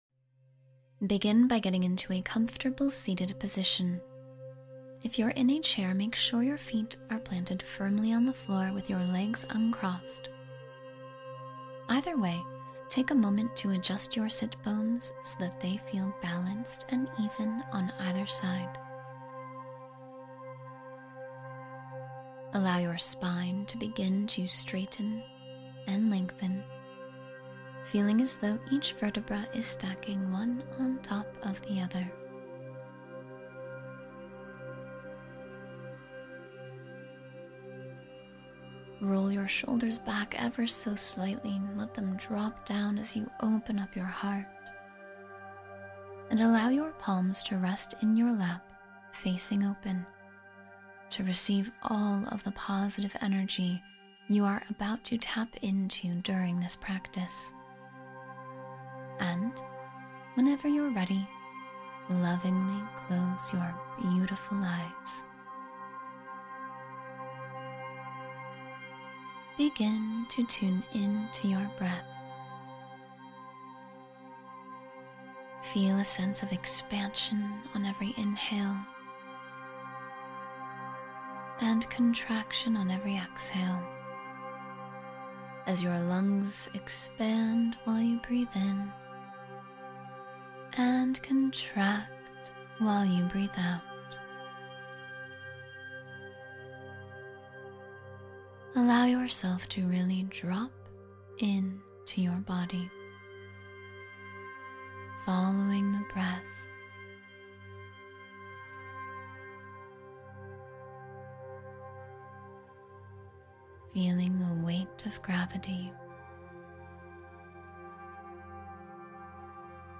Energize Yourself with Positivity — 10-Minute Morning Meditation